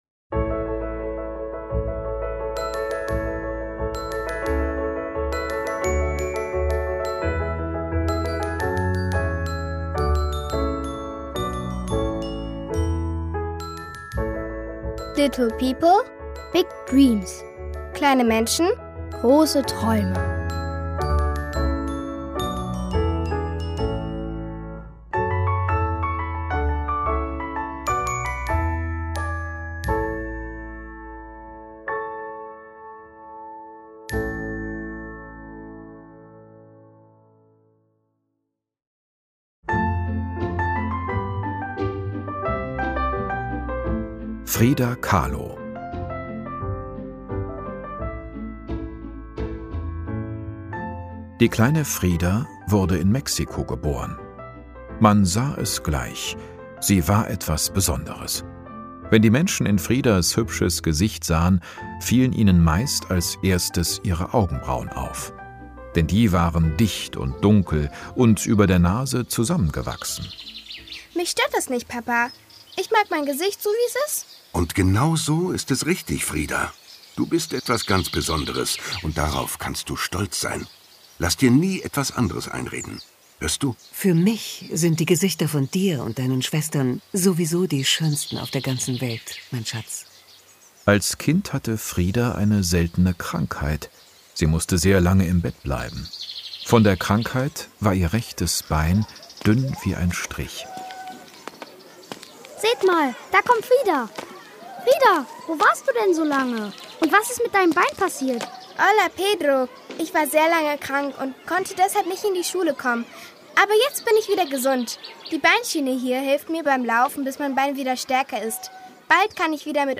Little People, Big Dreams® – Teil 3: Frida Kahlo, Rosa Parks, Marie Curie, Amelia Earhart Hörspiele